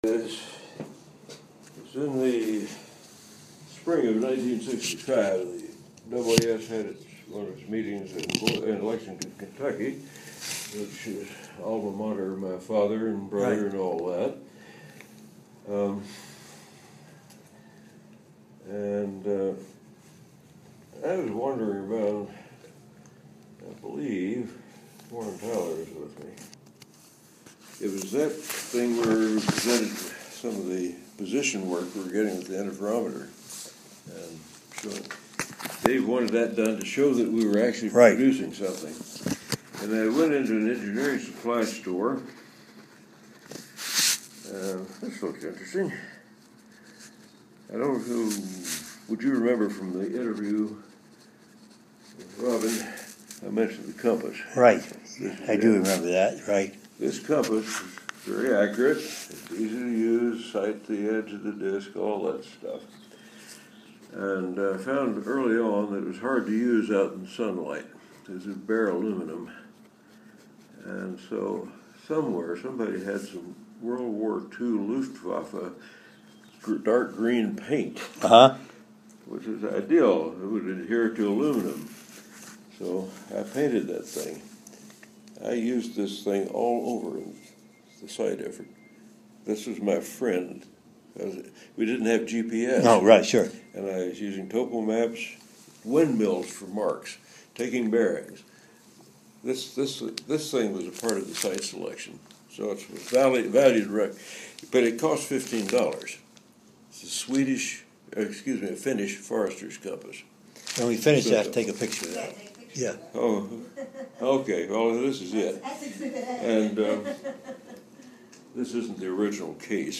Type Oral History
Location Albuquerque, NM